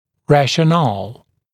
[ˌræʃə’nɑːl][ˌрэшэ’на:л]разумное объяснение; логическое обоснование